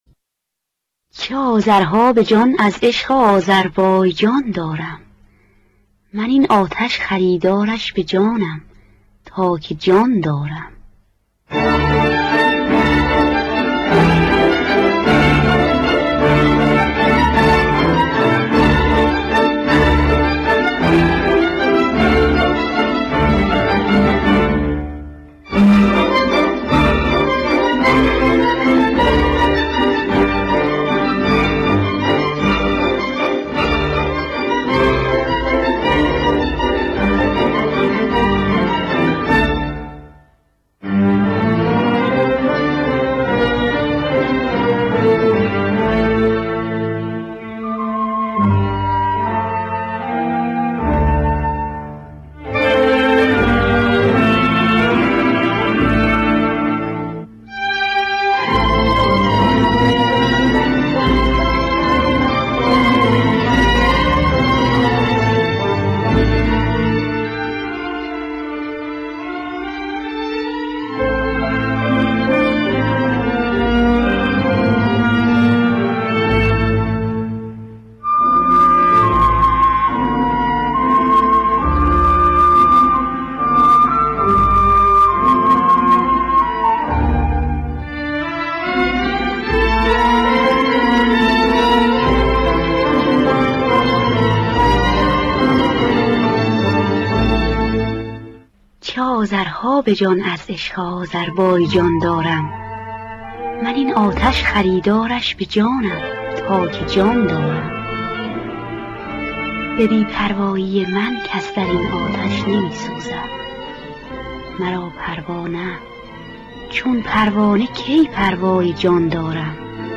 خوانندگان: بنان نوازندگان: روح‌الله خالقی